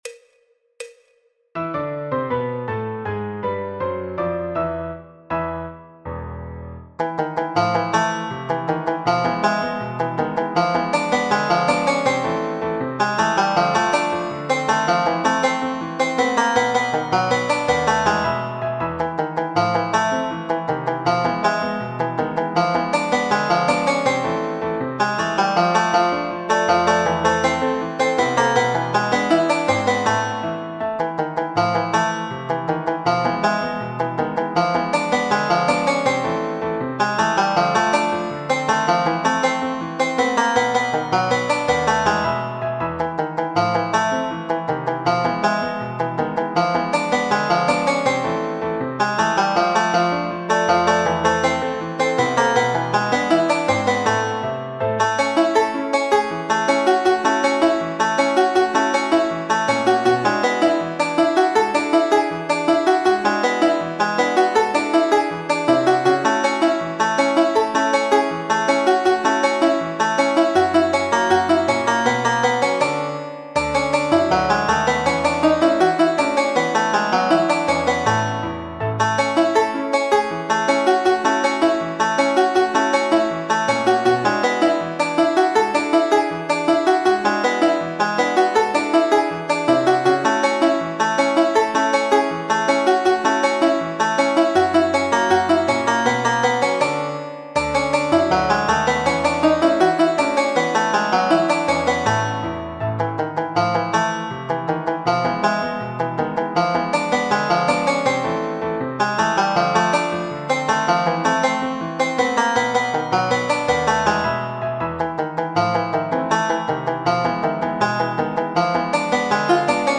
Choro, Jazz, Popular/Tradicional